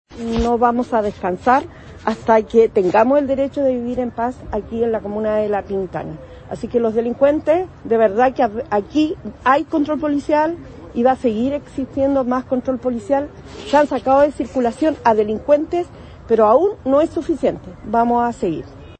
660-cuna-castillo-alcaldesa.mp3